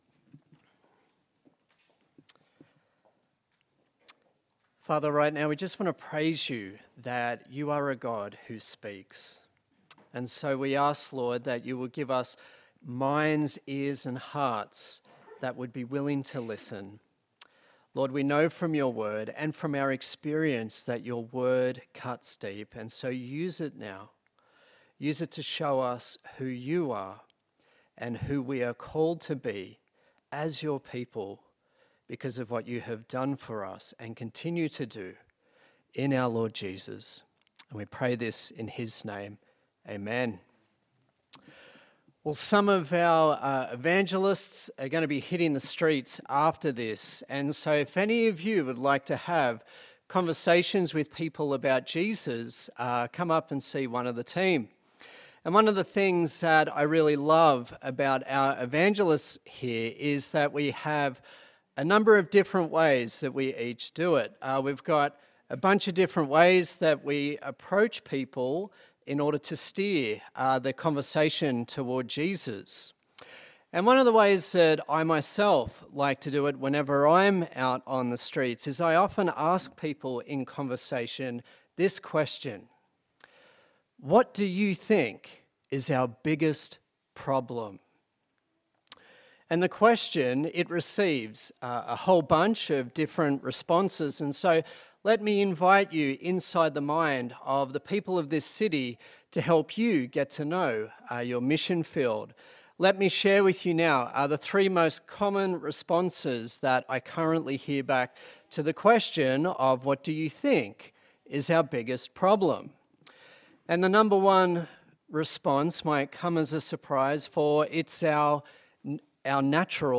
Acts Passage: Acts 19:23-20:6 Service Type: Morning Service